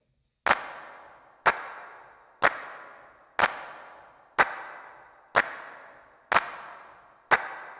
08 Clap.wav